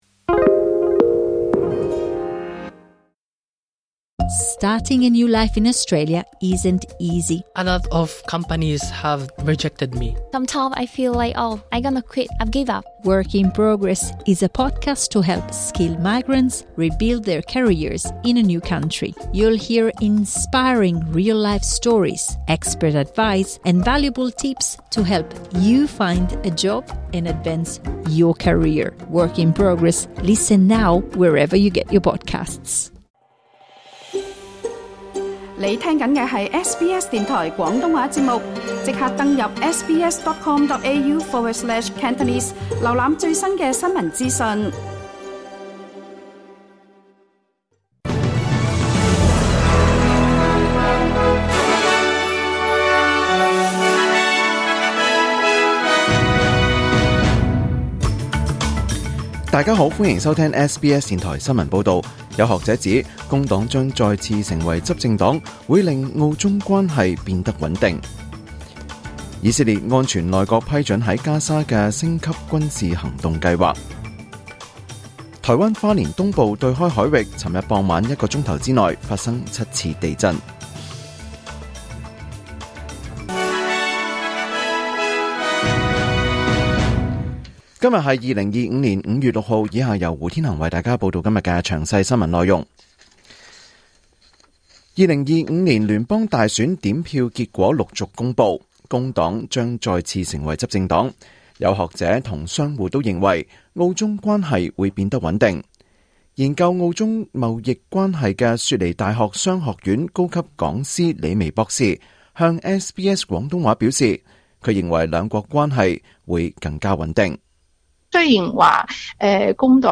2025 年 5 月 6 日 SBS 廣東話節目詳盡早晨新聞報道。